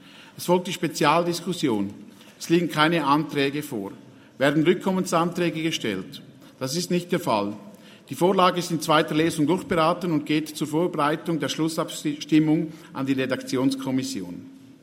Session des Kantonsrates vom 12. bis 14. Juni 2023, Sommersession
12.6.2023Wortmeldung